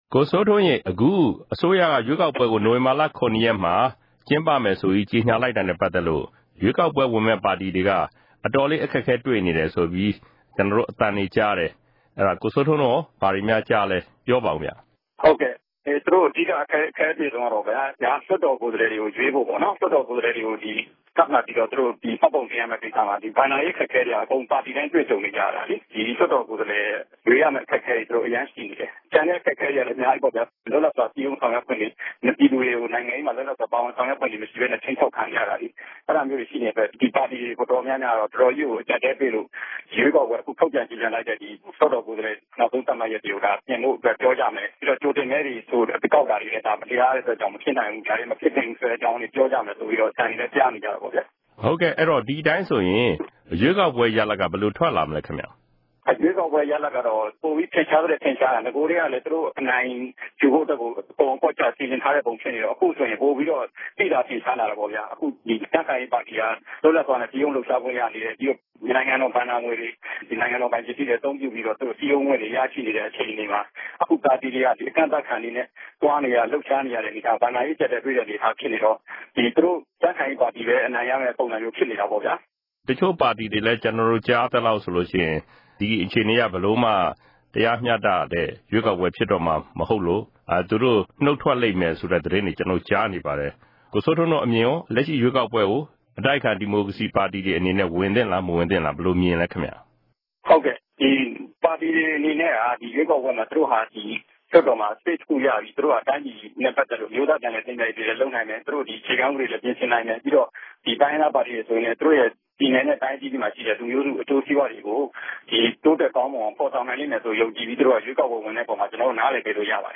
ဆက်သွယ်မေးမြန်းချက်။။